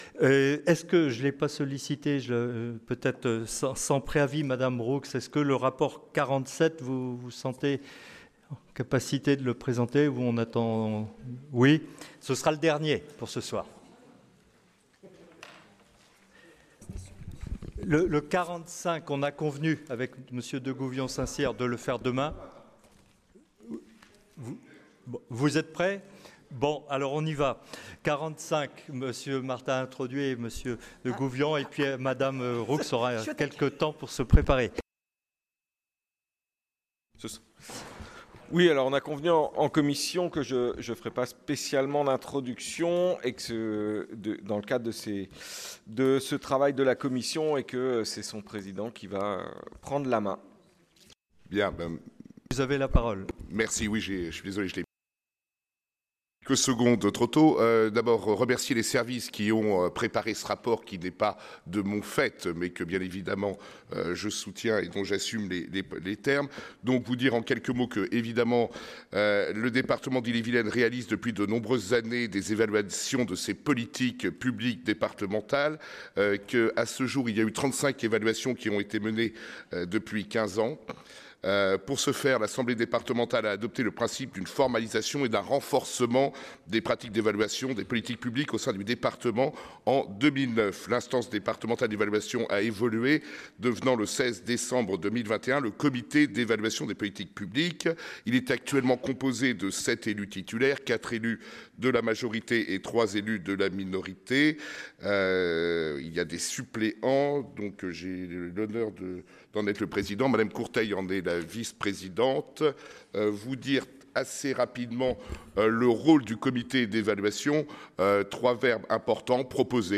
• Assemblée départementale du 19/03/25